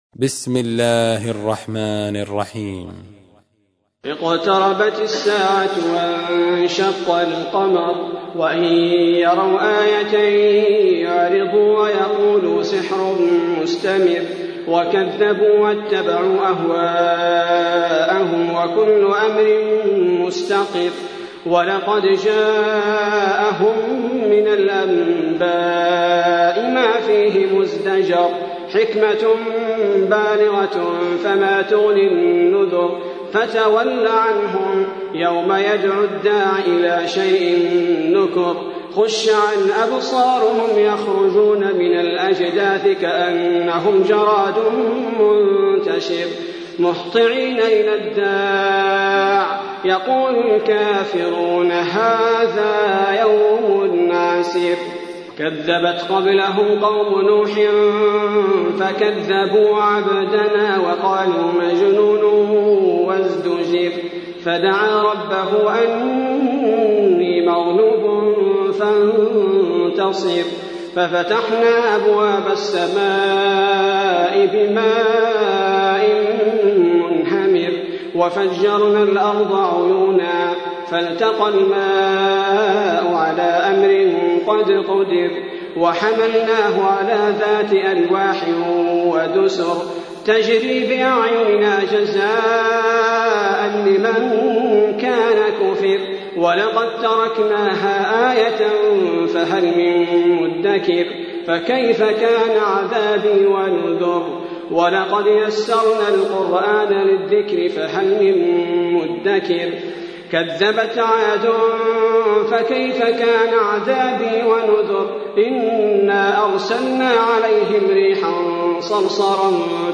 تحميل : 54. سورة القمر / القارئ عبد البارئ الثبيتي / القرآن الكريم / موقع يا حسين